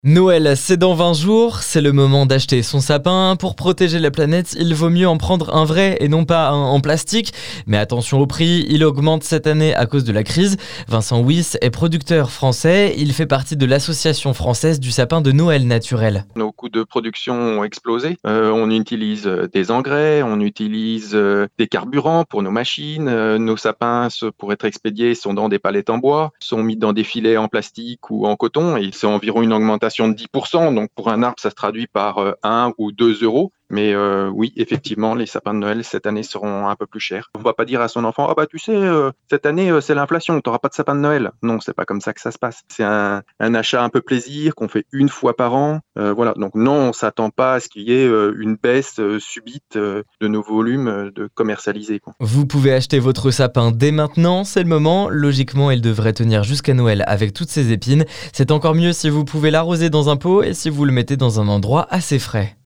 Il explique à notre micro que tous les coûts de production ont augmenté cette année.